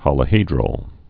(hŏlə-hēdrəl, hōlə-)